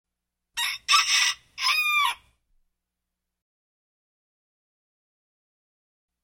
Пение петуха
Тут вы можете прослушать онлайн и скачать бесплатно аудио запись из категории «Животные, звери».